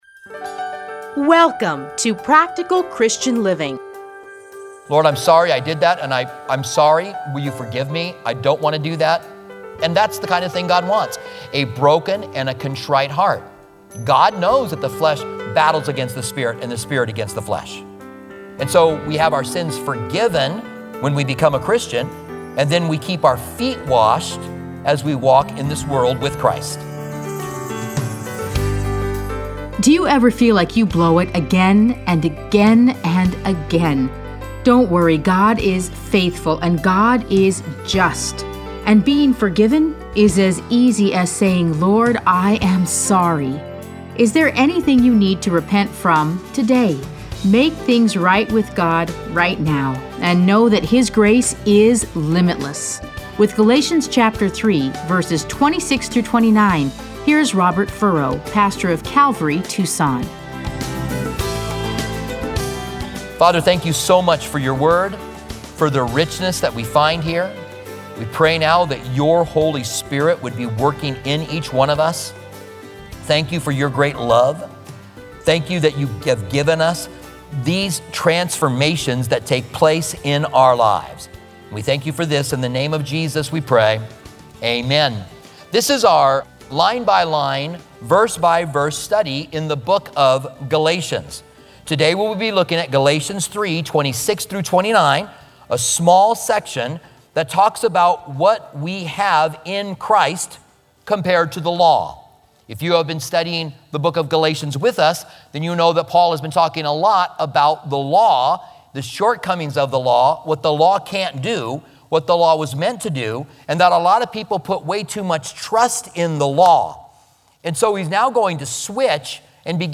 Listen to a teaching from Galatians 3:26-29.